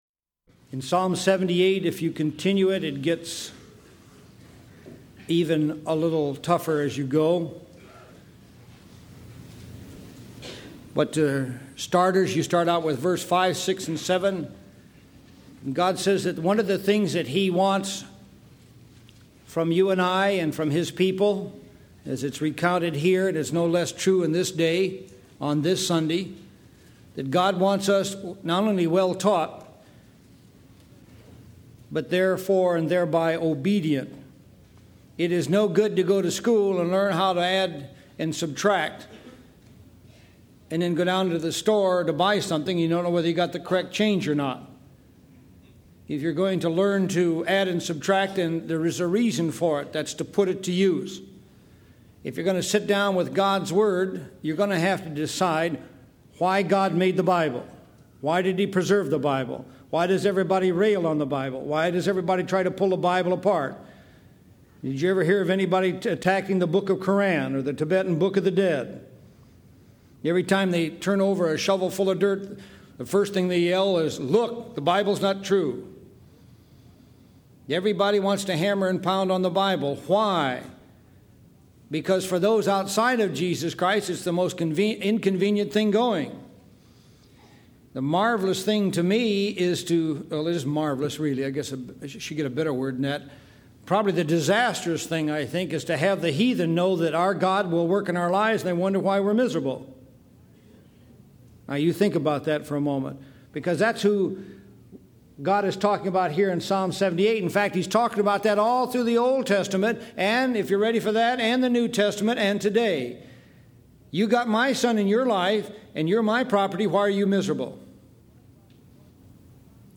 Who is First download sermon mp3 download sermon notes Welcome to Calvary Chapel Knoxville!